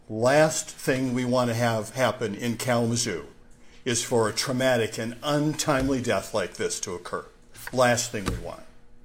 Kalamazoo Mayor David Anderson also spoke at the news conference, offering his condolences to the family of the suspect. He praised the actions of the officers, but also commiserated with them for having to take a life.